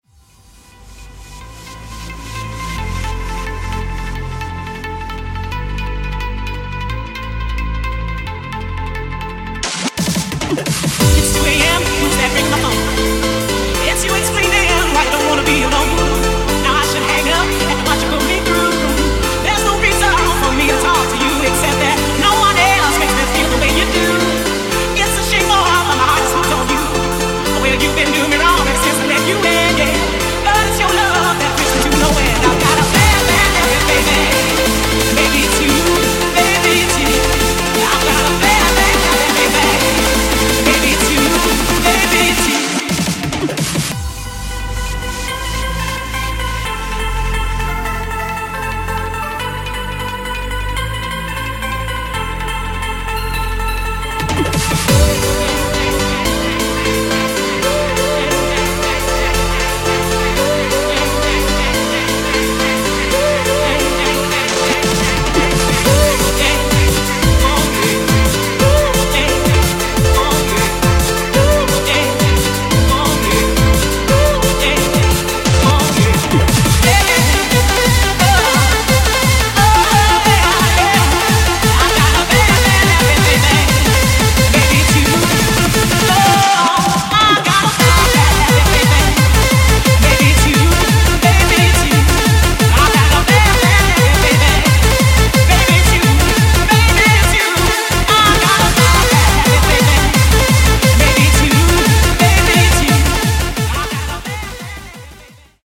BPM175
MP3 QualityLine Out